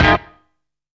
WAH HIT.wav